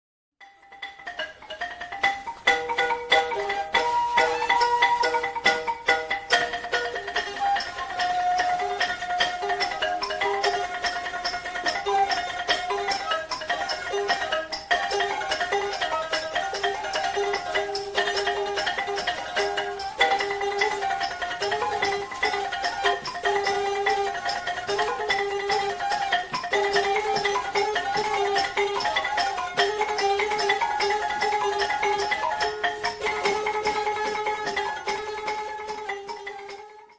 Lute＝Kechapi
Percussion＝Hesek
Suling
Xylophone＝Gerantung